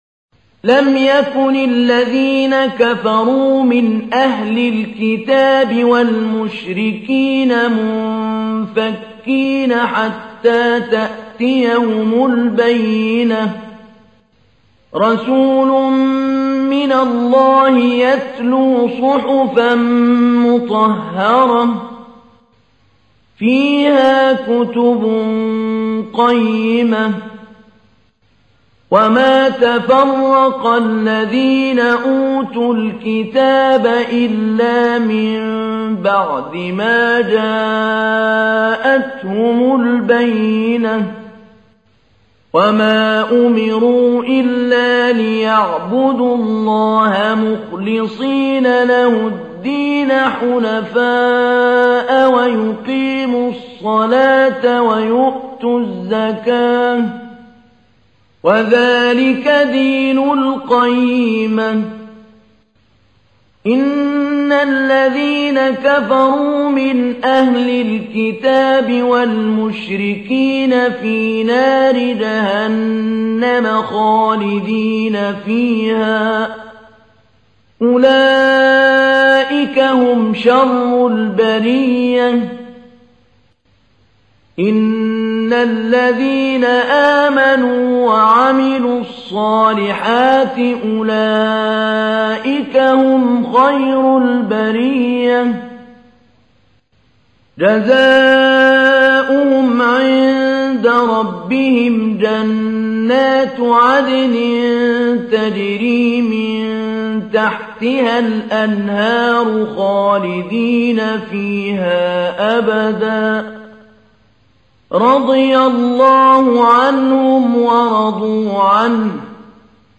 تحميل : 98. سورة البينة / القارئ محمود علي البنا / القرآن الكريم / موقع يا حسين